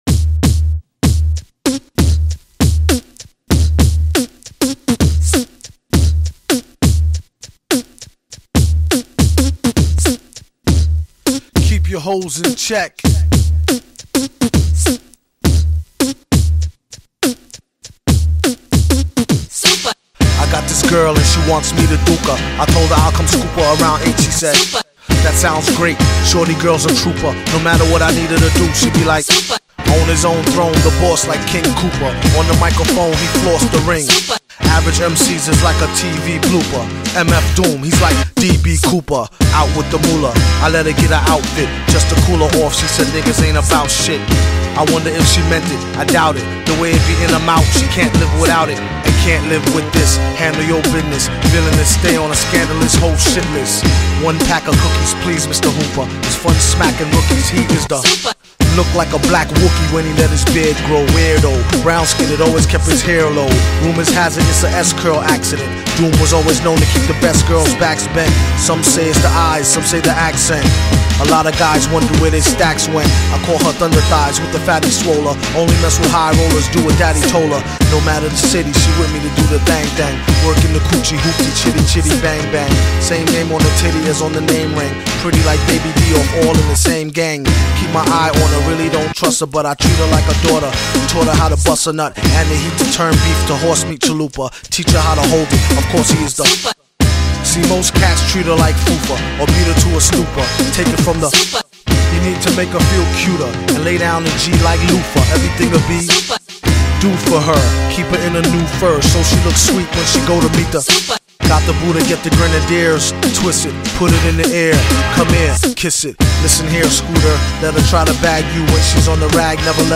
Me gusta mucho su estilo de Rap.